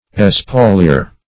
Search Result for " espauliere" : The Collaborative International Dictionary of English v.0.48: Espauliere \Es`pau`liere"\, n. [OF.